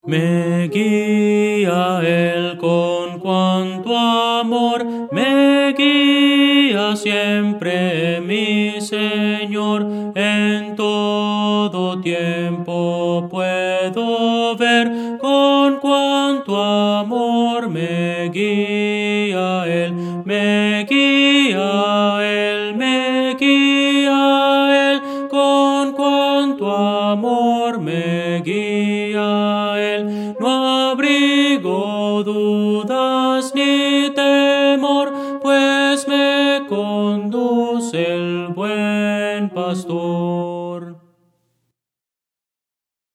Voces para coro